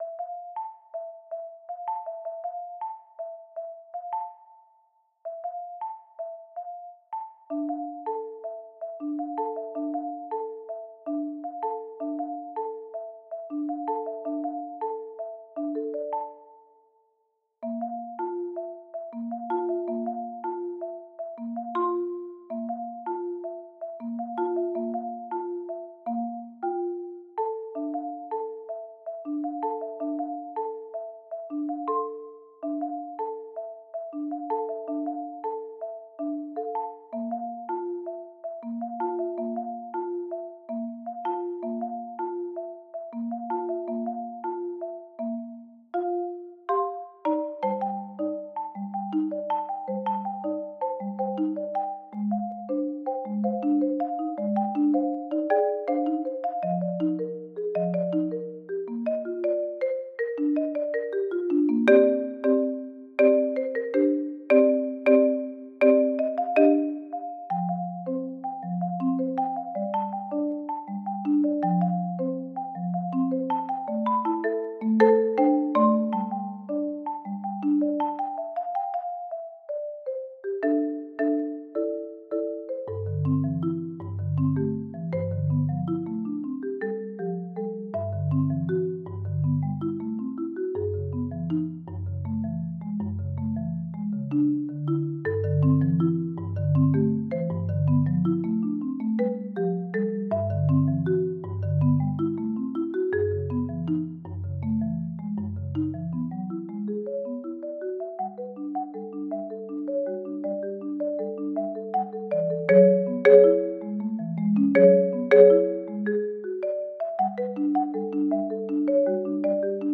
Genre: Duet for 2 Marimbas
Marimba (5-octave)